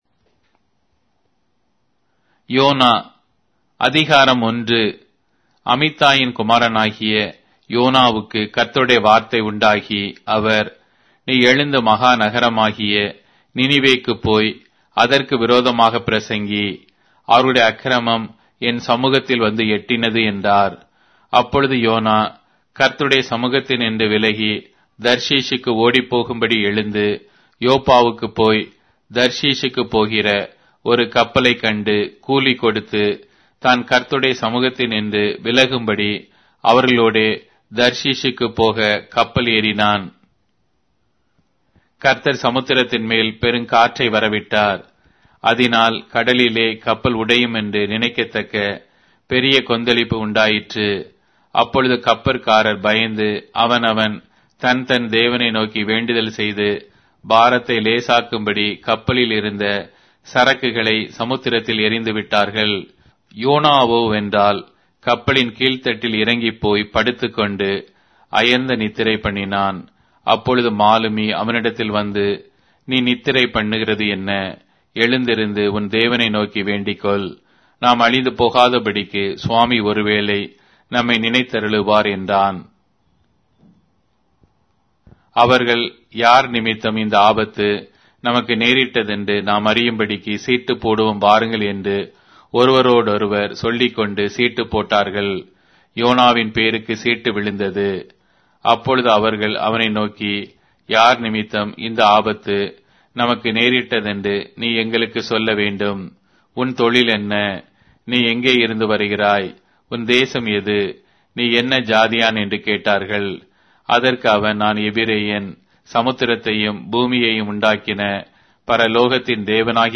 Tamil Audio Bible - Jonah 3 in Gnttrp bible version